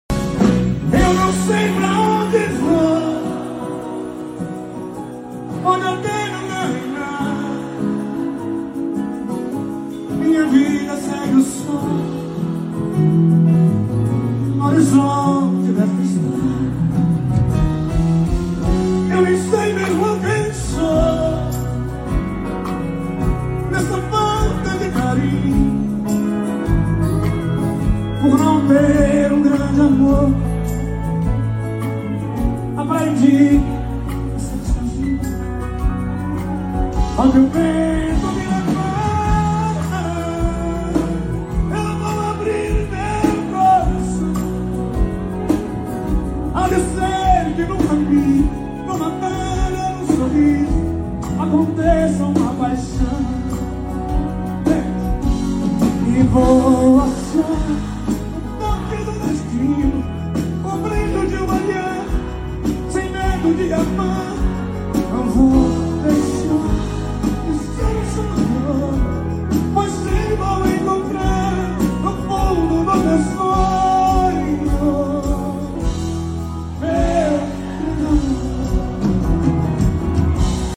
O cantor embalou o público com sucessos românticos